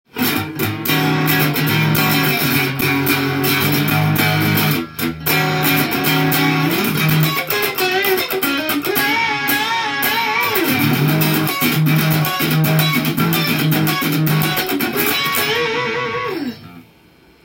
センターピックアップでも弾いてみました。
ジミヘンドリックスになった気分になるので、心地よいギターサウンドです。
カッティングでジャカジャカしても良いですし、ギターソロも映える感じがします！